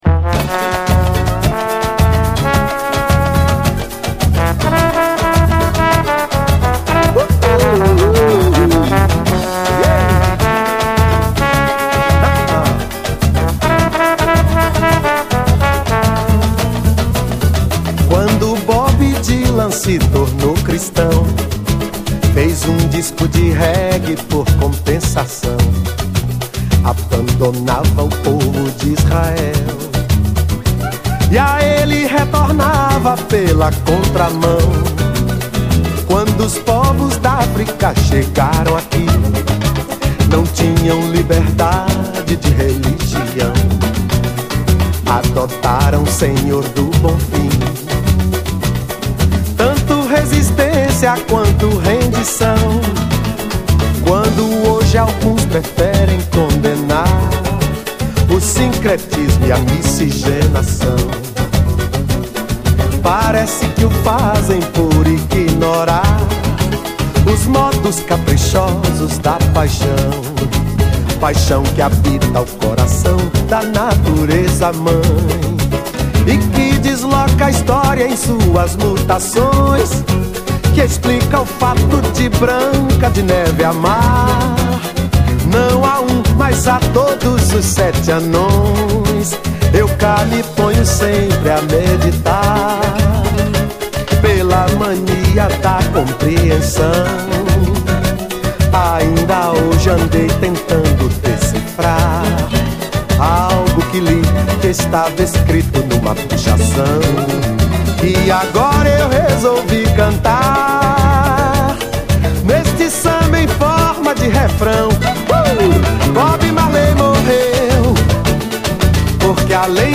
BRAZIL
ピュアな歓びに満ちたラララ・コーラスが素晴らしい！